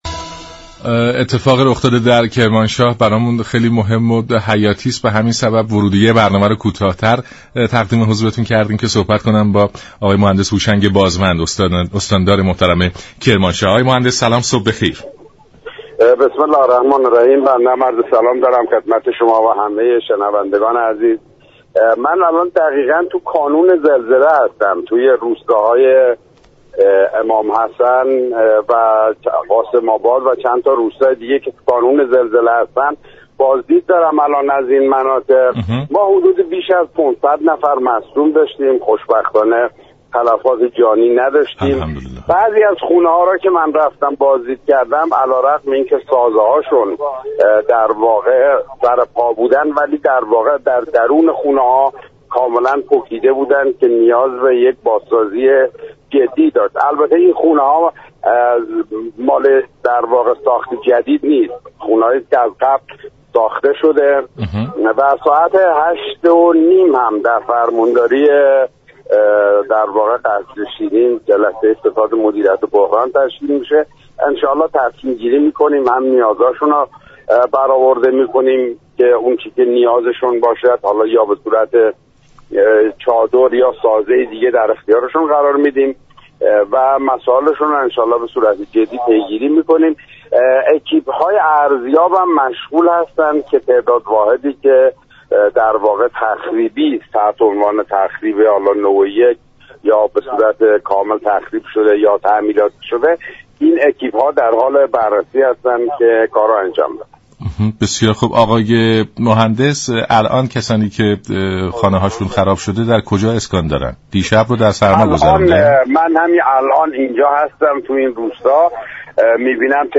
استاندار كرمانشاه در گفت و گو با رادیو ایران گفت: ستاد مدیریت بحران در صدد است با تصمیم گیری مناسب به نیازها و ضروریات هموطنان آسیب دیده پاسخ دهد و شرایط را به حالت عادی بازگرداند.